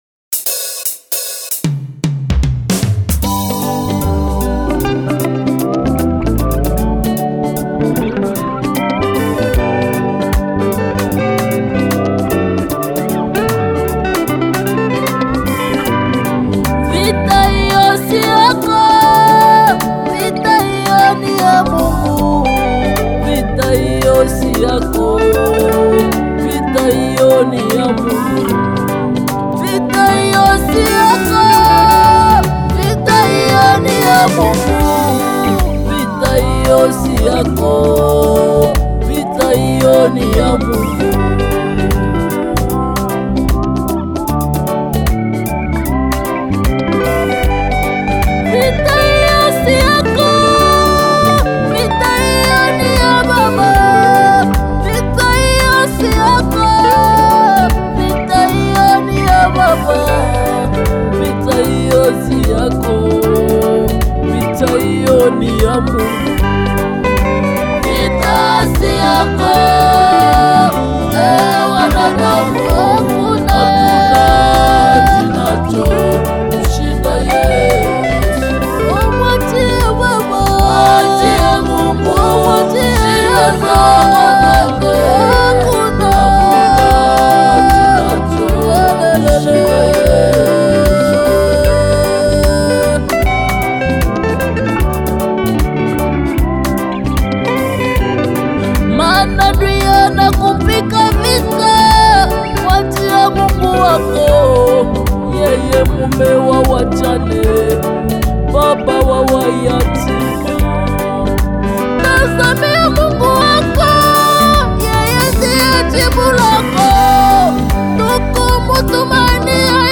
Fast-rising gospel artist from Congo